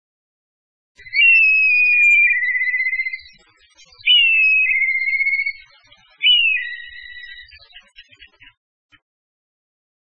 2125e「鳥の鳴声」
〔トビ〕ピーヒョロヒョロ／キーイッキイッキイッキ，クィーウキッキッキッ／動物の